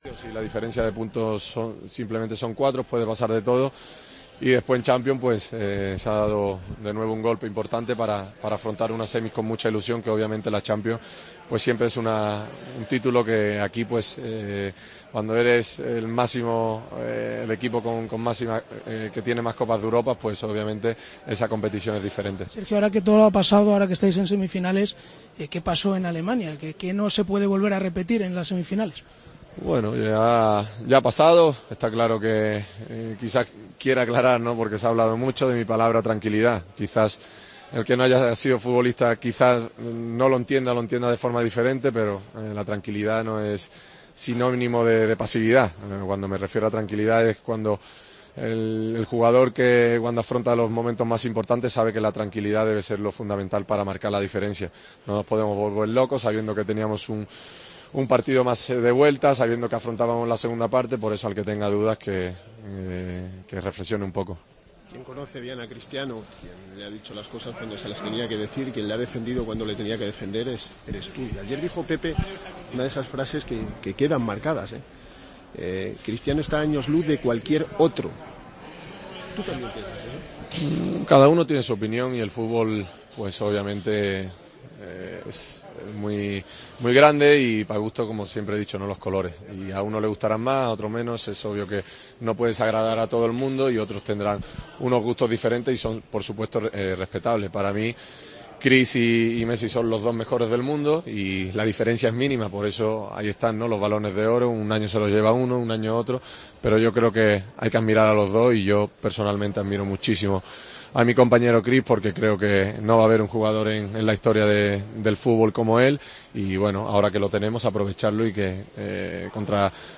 Escuchamos al capitán del Real Madrid después de clasificarse por sexta vez consecutiva para unas semifinales de la Liga de Campeones, tras ganar al Wolsfburgo 3-0 con un 'hat-trick' de Cristiano: "El que tengas dudas que reflexione un poco.